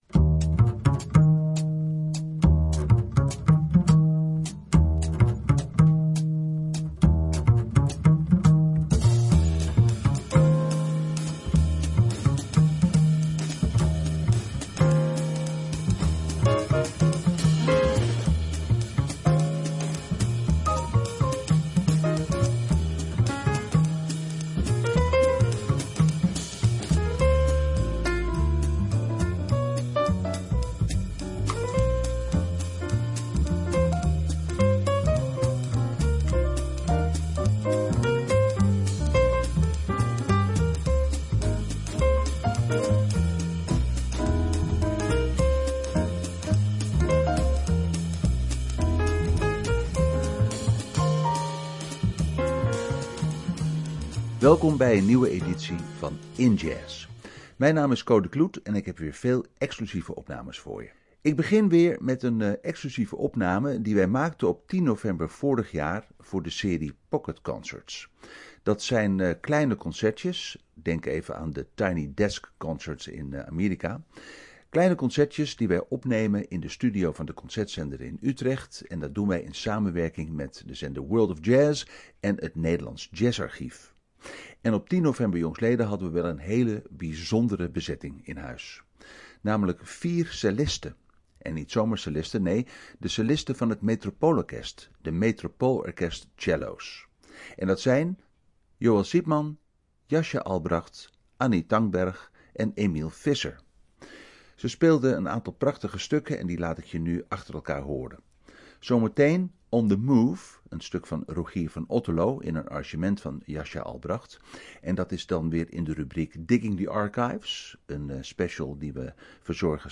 Centraal staat de promotie van jazz en beyond. De Metropole Cellos staan deze keer centraal.